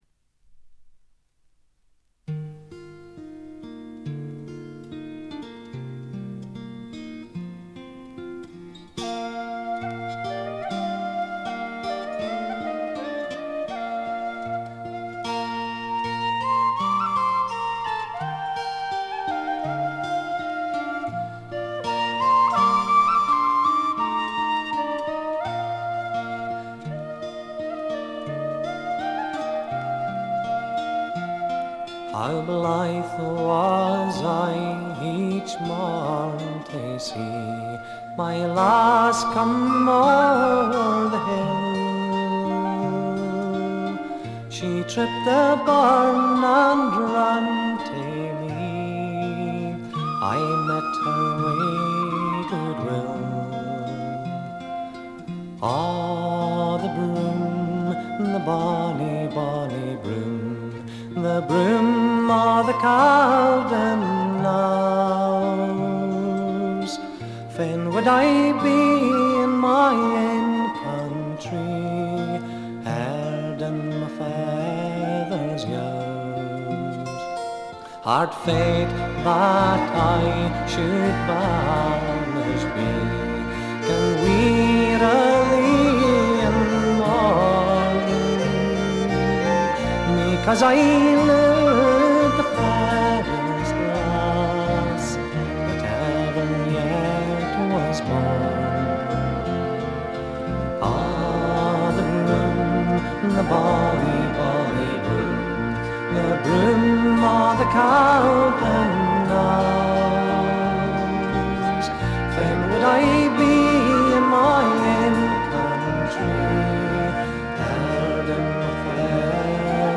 70年にエディンバラで結成されたスコットランドを代表するトラッド・バンド。
試聴曲は現品からの取り込み音源です。
Fiddle, viola, bouzouki, mandolin, mandola, vocals
Guitar, mandola